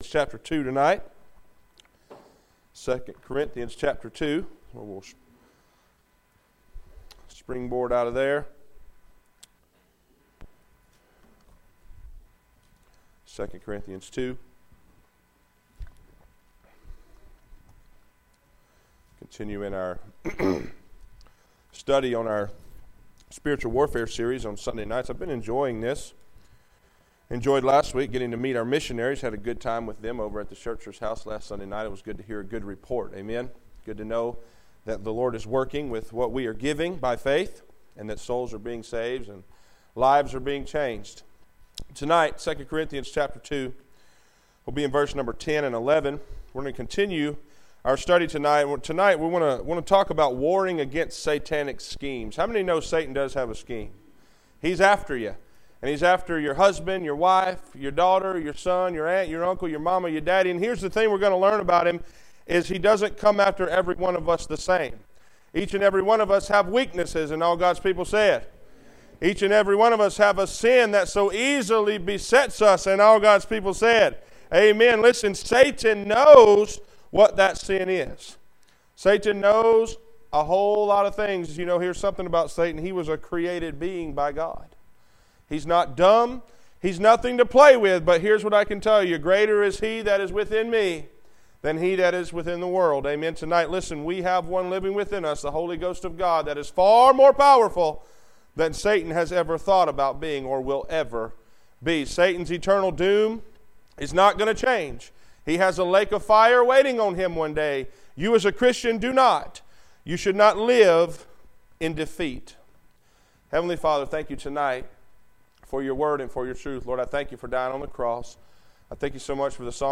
Passage: 2 Cor 2:10-11, Eph 6:10-18 Service Type: Sunday P.M.